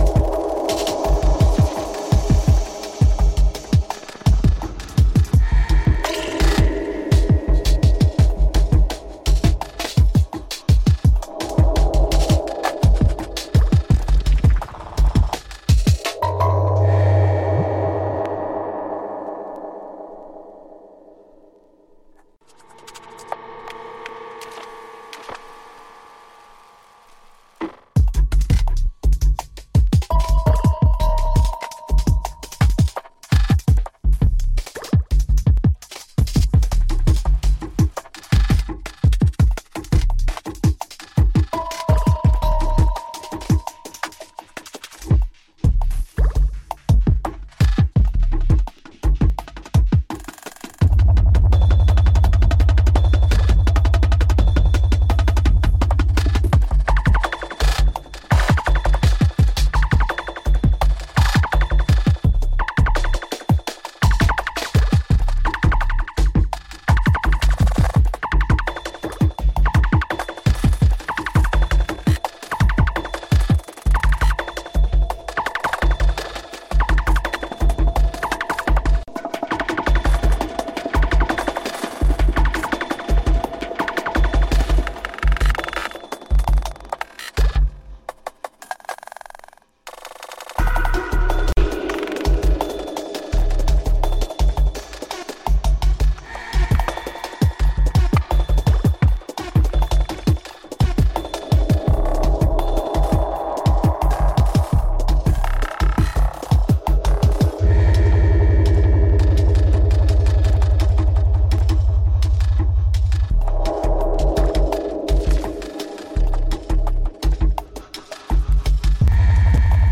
グリッチー・ハーフタイム・ドラムンベース